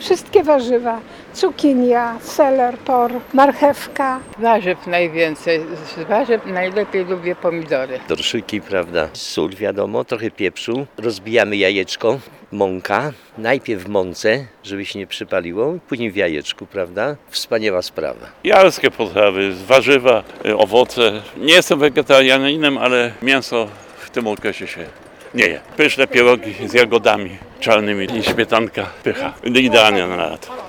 Byliśmy na miejskim ryneczku, gdzie pytaliśmy zielonogórzan, jakie są ich ulubione letnie potrawy oraz przysmaki: